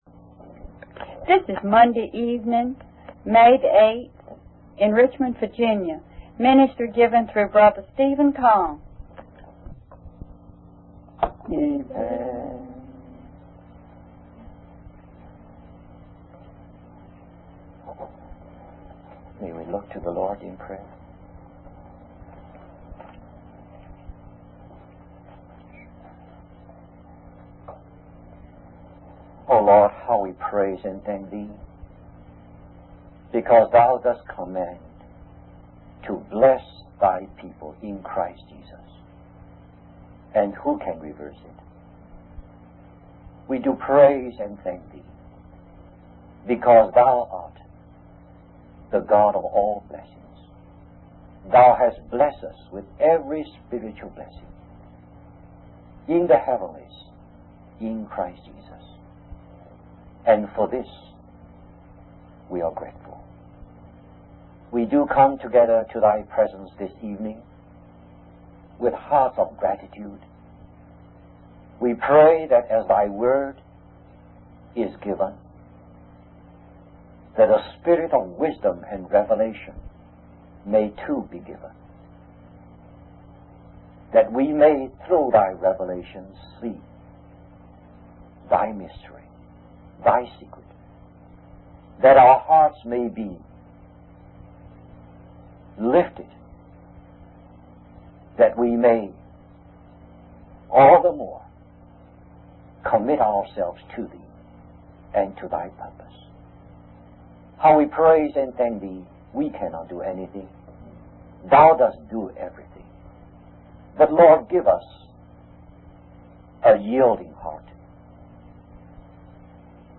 In this sermon, the preacher emphasizes that Jesus, during his time on earth, manifested God in every way.